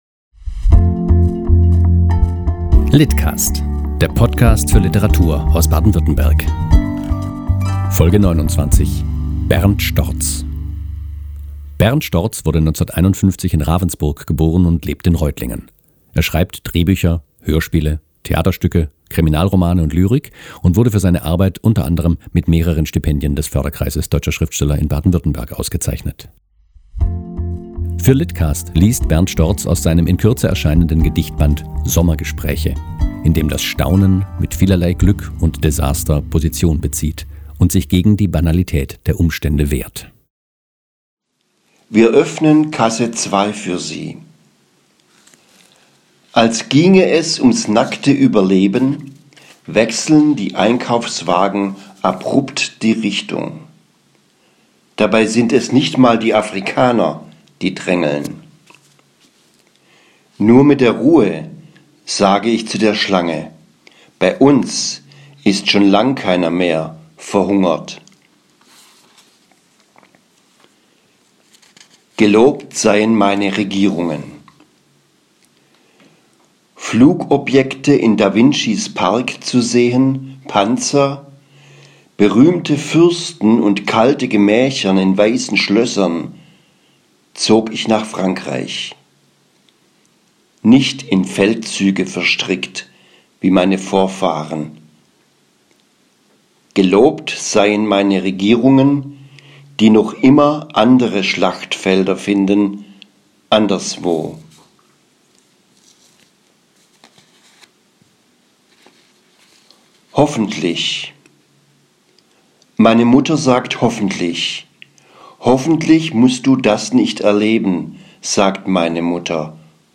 liest aus seinen Gedichten